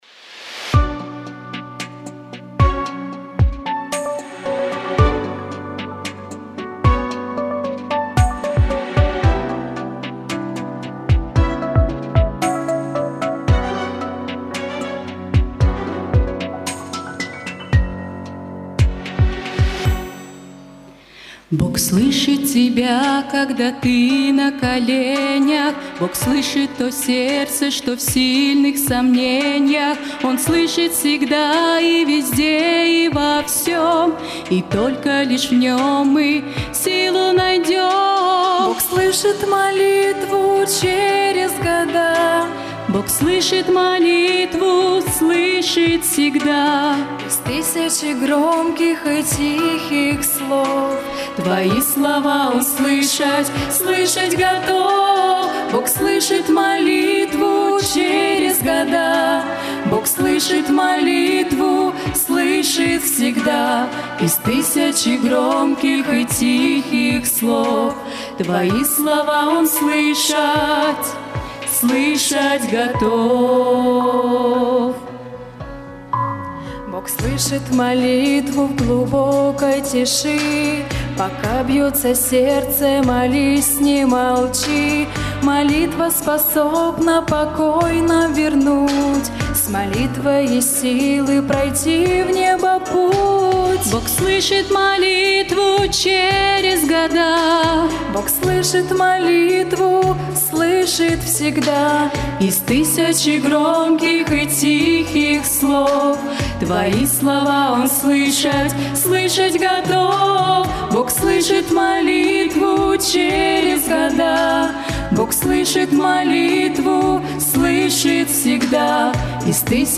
Богослужение 28.09.2024
Пение